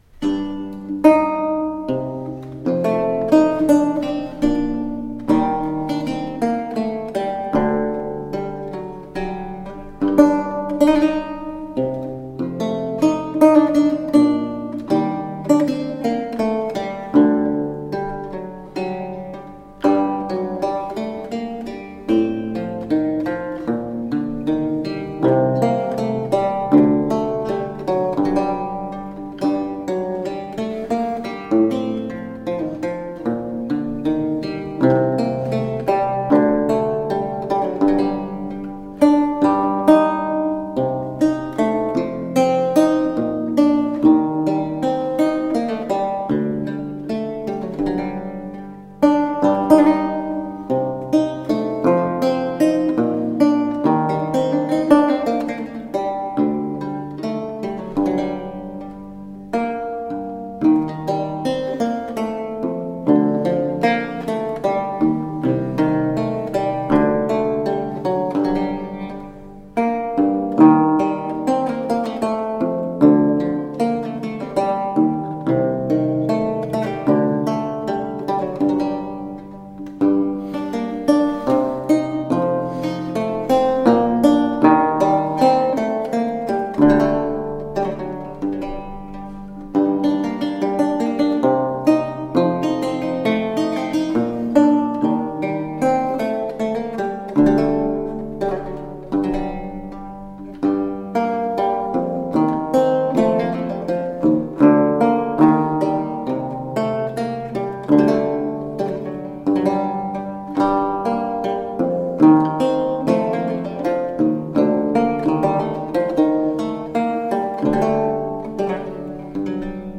A feast of baroque lute.
Tagged as: Classical, Baroque, Instrumental, Lute